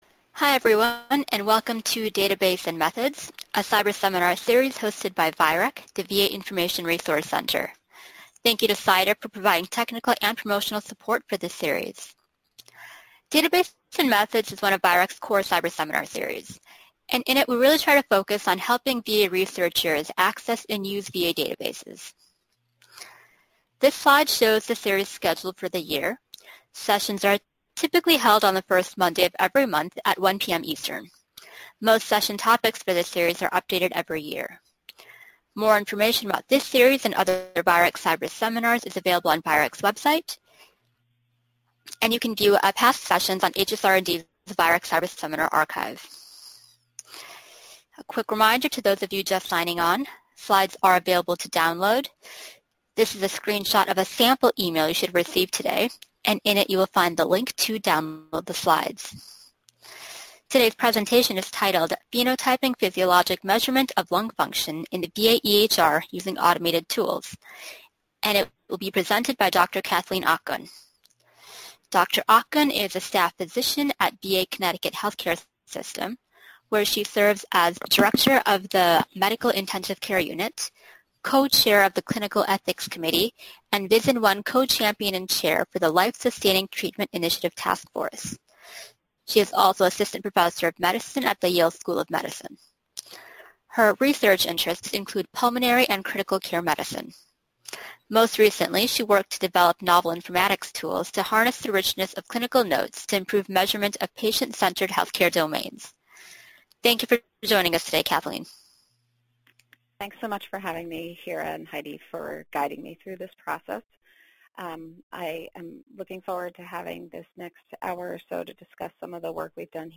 VIReC Database and Methods Seminar